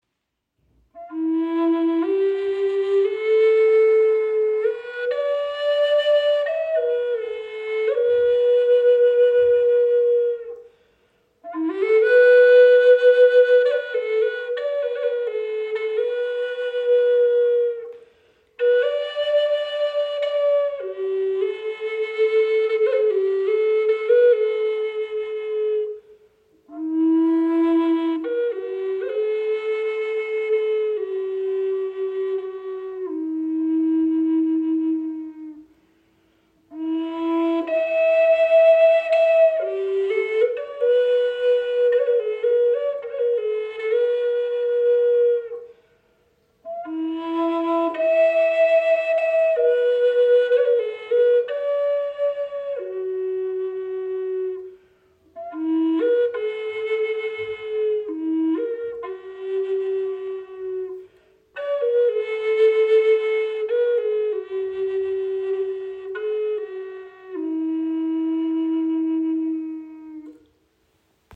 • Icon Gestimmt auf 432 Hz – für besonders harmonischen, erdenden Klang
Diese EarthTone Bassflöte in E-Moll (432 Hz) aus aromatischer Zeder überzeugt mit einem warmen, erdigen Klang und einer sanften Tiefe.
Die Zederflöten überzeugen mit einem sanften, warmen Klang, der sich besonders gut für sanfte Melodien und meditative Musik eignet. Das Walnussholz verleiht der Flöte eine klare, volle Stimme mit erdiger Tiefe, die auch in größeren Räumen oder draußen weit hörbar bleibt.